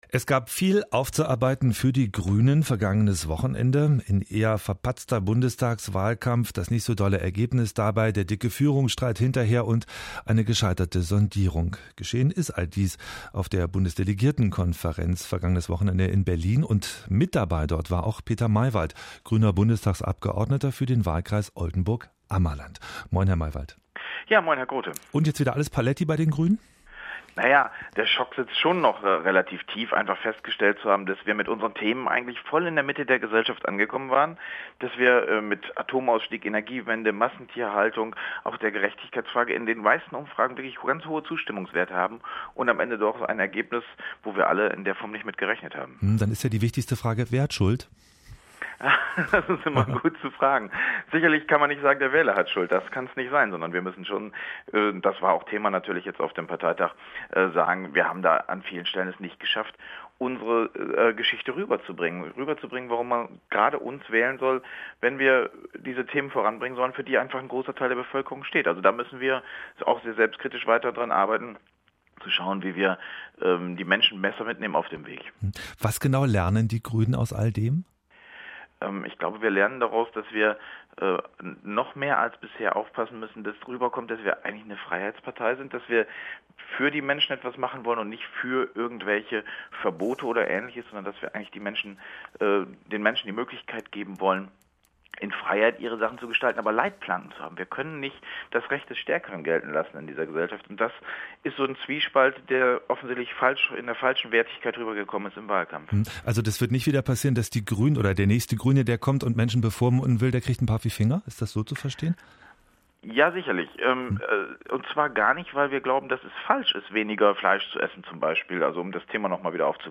Gespräch am frühen Morgen, einen Tag vor der Konstituierenden Sitzung, (4:57 Minuten) (nordwest radio, 21. Oktobber 2013)
Gespräch-mit-Peter-Meiwald.mp3